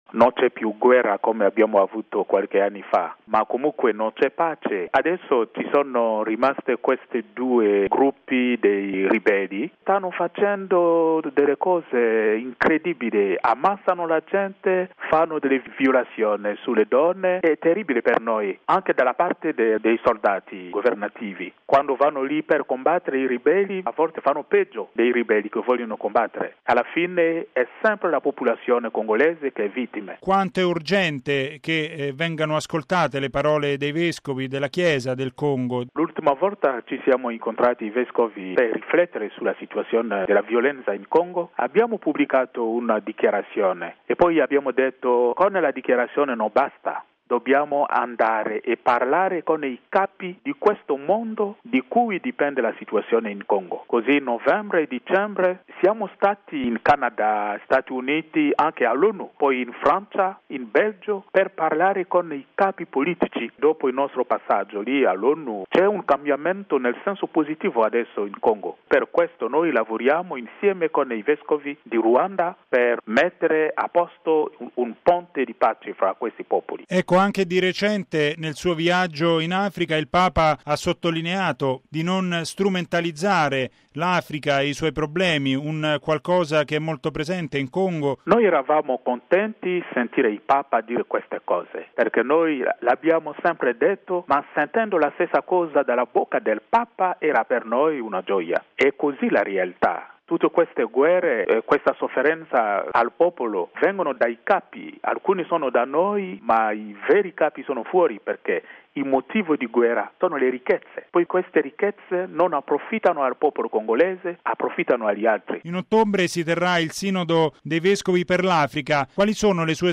Il difficile cammino per la pace nella Repubblica Democratica del Congo: la testimonianza del vescovo congolese Fridolin Ambongo